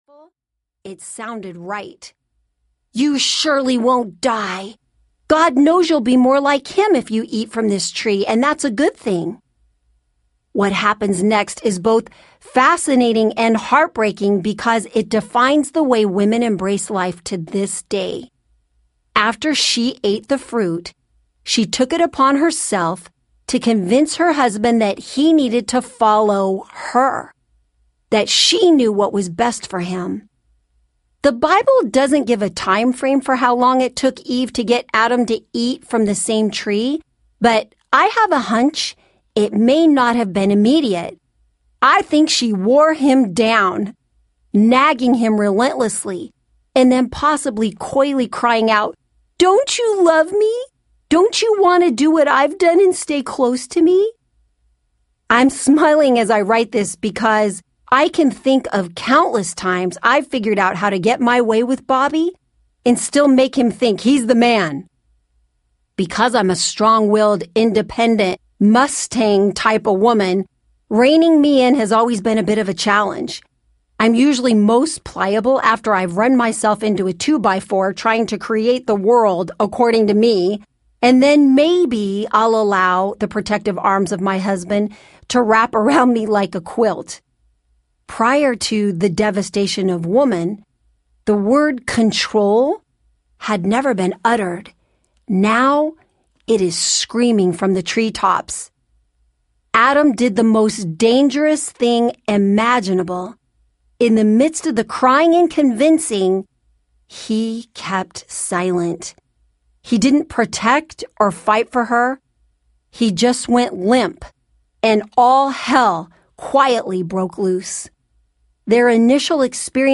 Spirit Hunger Audiobook